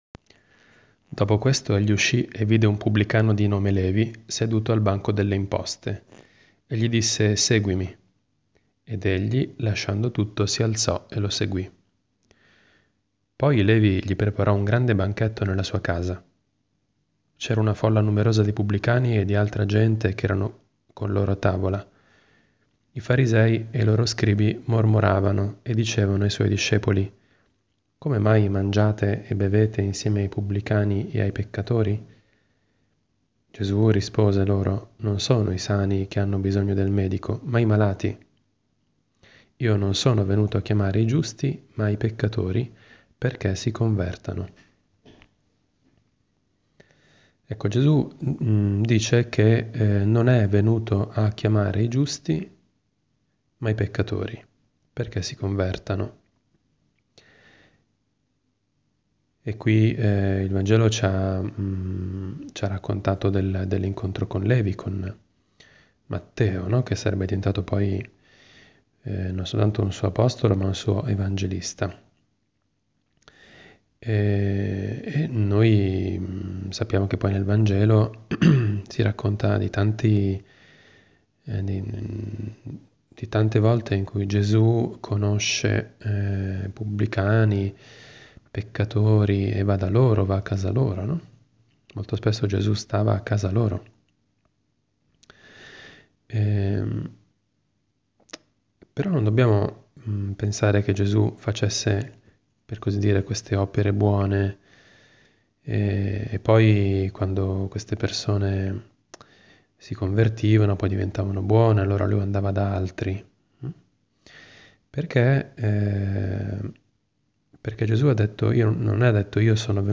Commento al vangelo (Lc 5,27-32) del 17 febbraio 2018, sabato dopo le Ceneri.